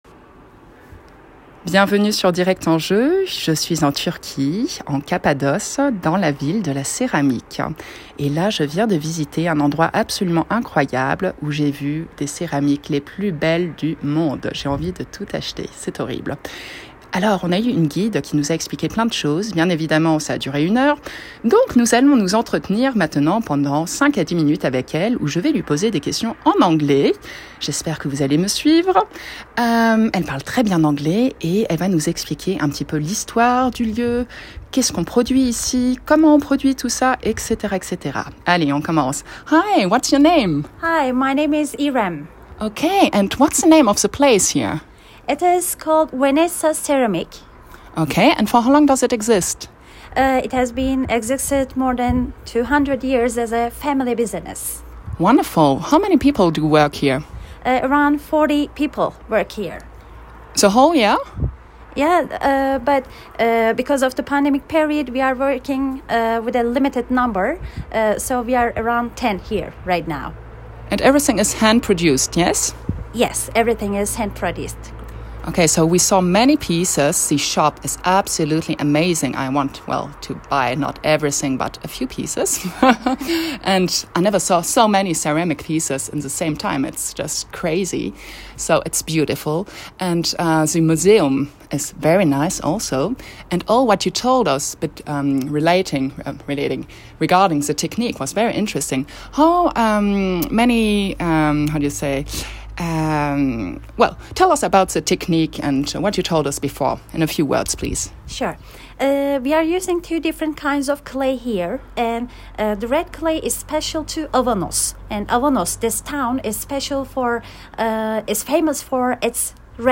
en direct de Turquie pour Avanos Ceramic